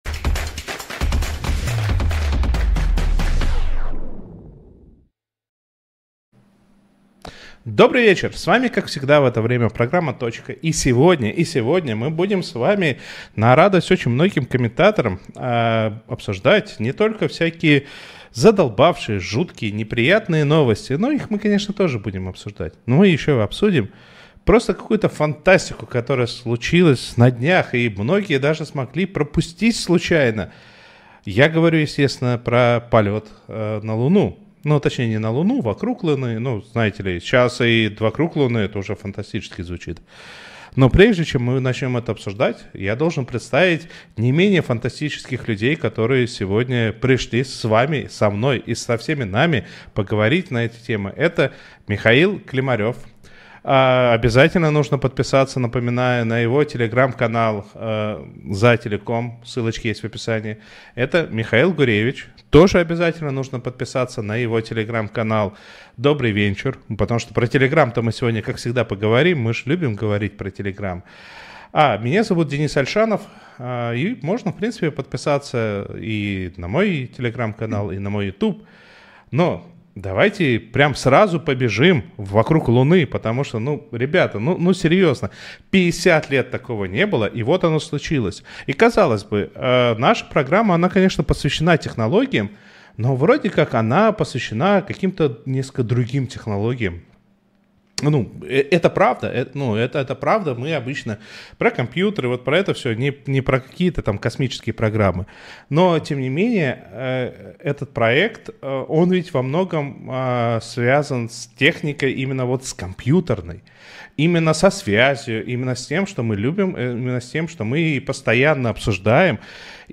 говорит с экспертами про интернет и технологии в нашей жизни
эксперт по рынку интернета